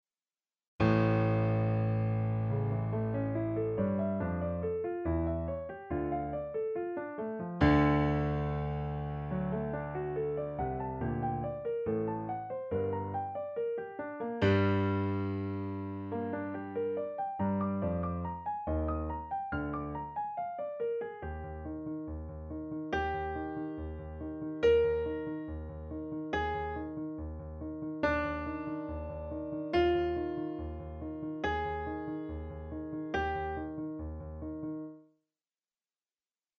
The music is created by electronic sounds and instruments, which are sequenced by musicians who become the author. The sounds, therefore, do not come from an orchestra, but the result is often pleasenty surprising.
Introduction for a piano Sonata.